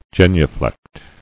[gen·u·flect]